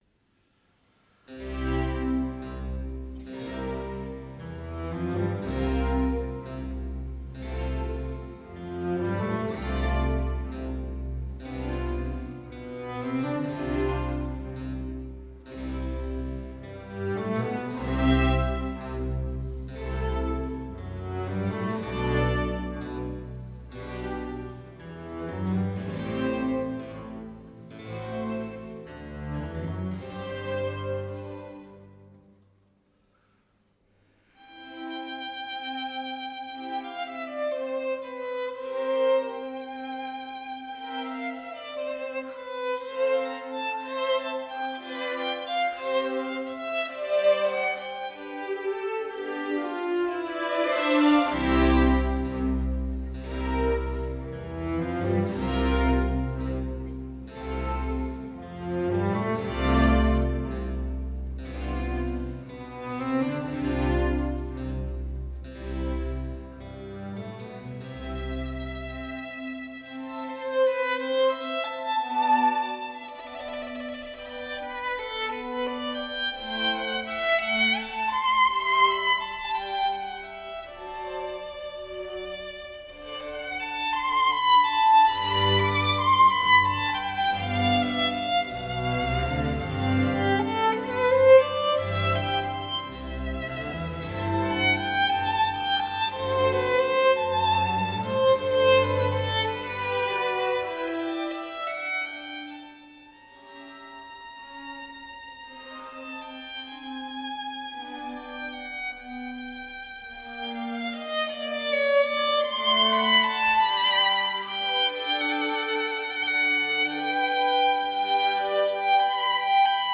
“Violin Concerto No. 1 in A Minor, II. Andante” by Johann Sebastian Bach, performed by Daniel Lozakovich and Kammerorchester des Symphonieorchesters des Bayerischen Rundfunks:
j.s.-bach-violin-concerto-no.-1-in-a-minor-bwv-1041-ii.-andante.mp3